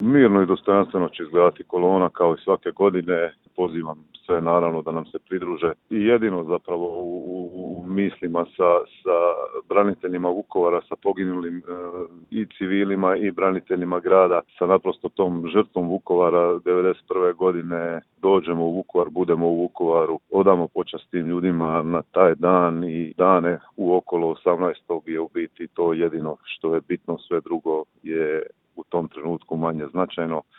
ZAGREB - Uoči obilježavanja vukovarske tragedije kratko smo razgovarali s gradonačelnikom Vukovara Ivanom Penavom.